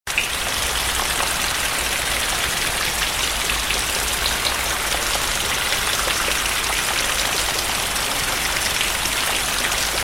Sound Effects Ringtones